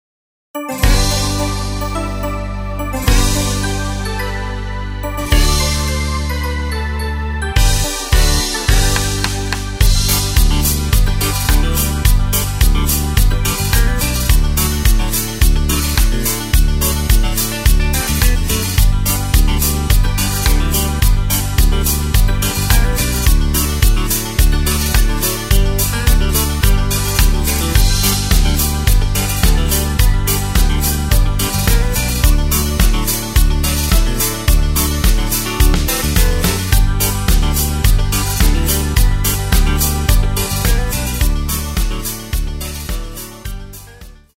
Tempo:         107.00
Tonart:            G
Country Song aus dem Jahr 1986!
Playback mp3 Demo